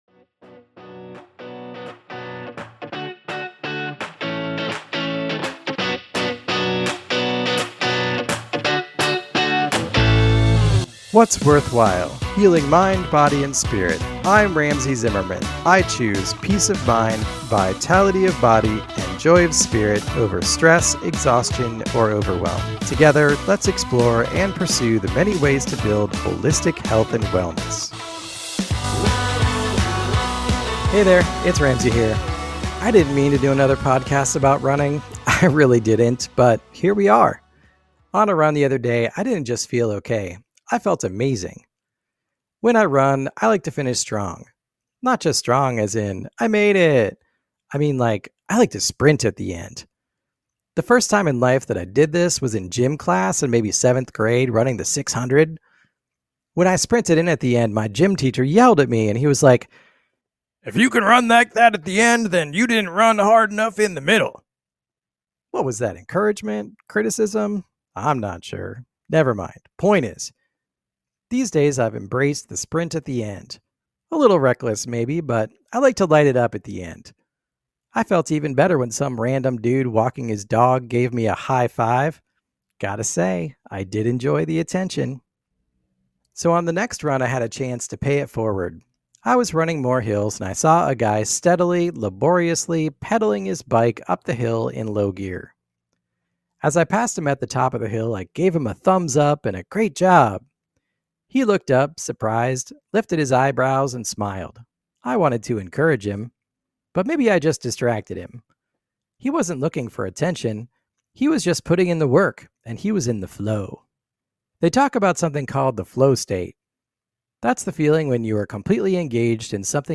Here’s another solo message not really about running.  It’s about finding and doing that thing that YOU can do, that puts you into the Flow State, where everything else melts away.